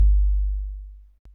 Index of /90_sSampleCDs/Northstar - Drumscapes Roland/KIK_Kicks/KIK_H_H Kicks x
KIK H H RA0F.wav